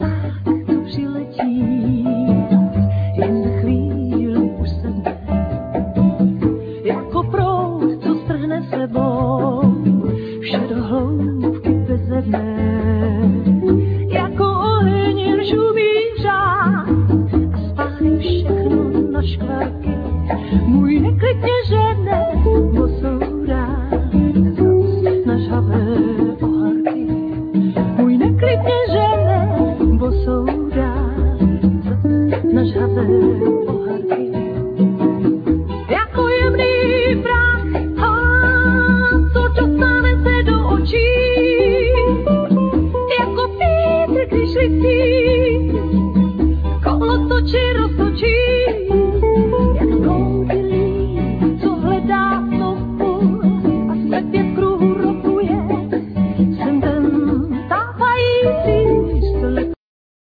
Vocal
El.+Ac.steel guitar
El.+Ac.nylon string guitar
Double-bass
Percussions
Piano